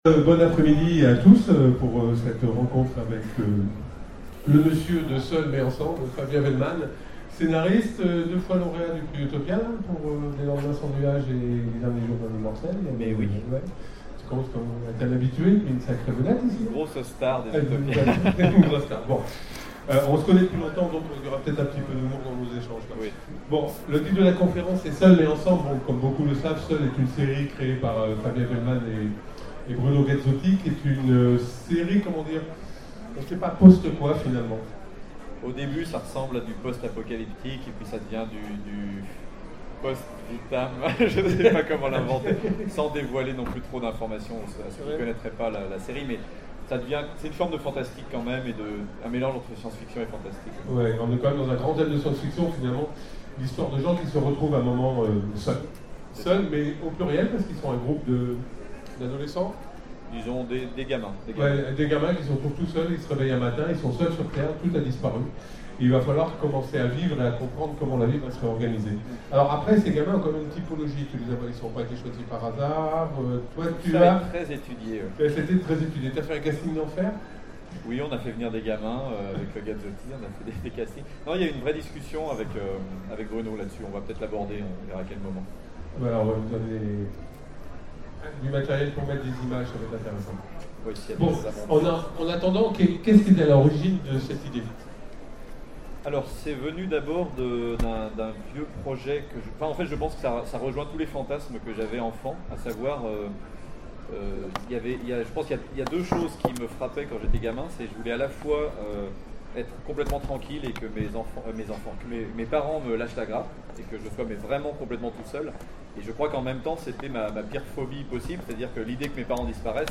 Utopiales 12 : Conférence Seuls mais ensemble !